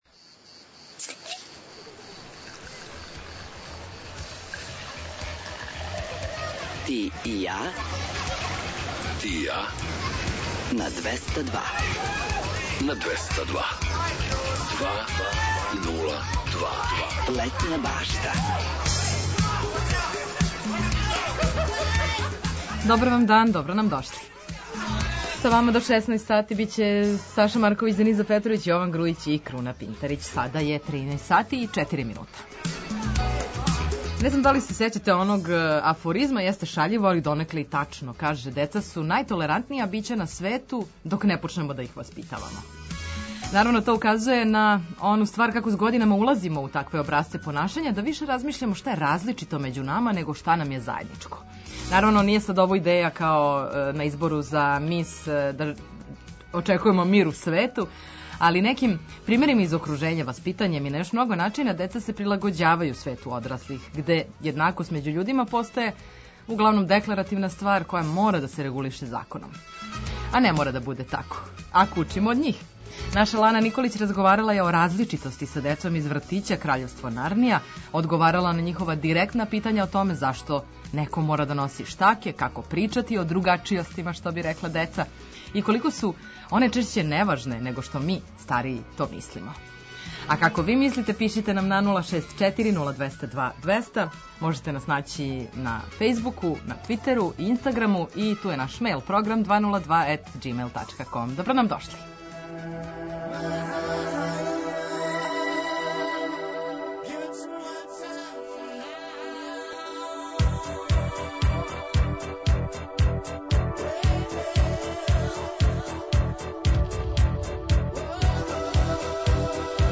У наставку емисије очекује вас више музике, приче о песмама, важним албумима, рођенданима музичара, а завирићемо и у највеће хитове светских топ листа. Обрадоваће вас и пола сата „домаћица”, песама из Србије и региона. Предлажемо вам предстојеће догађаје широм Србије, свирке и концерте, пратимо сервисне информације важне за организовање дана, а наш репортер је на градским улицама, са актуелним причама.